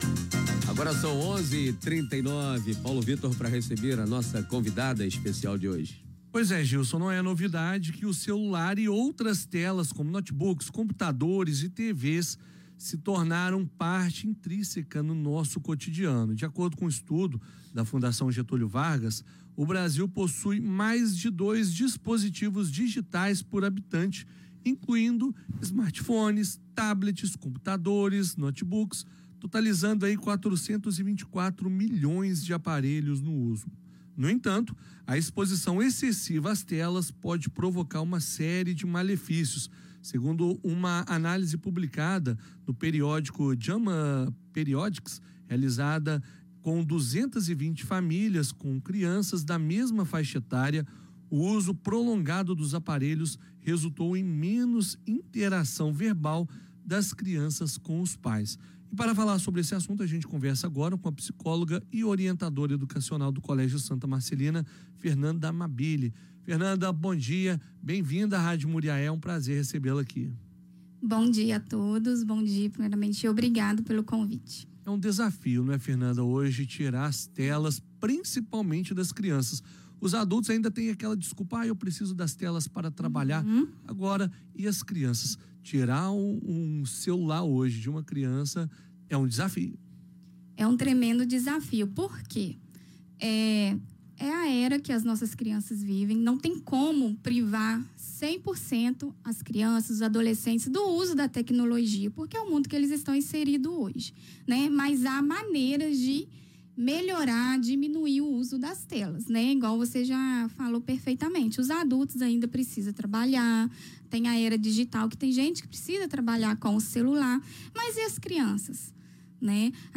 Entrevista-1-Radio-Muriae-Uso-de-telas-1.mp3